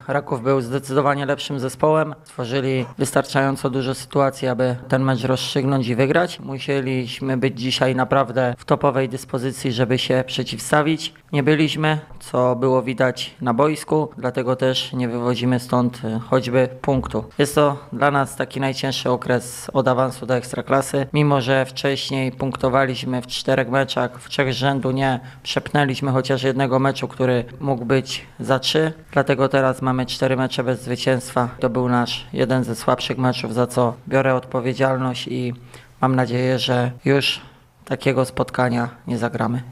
Na konferencji pomeczowej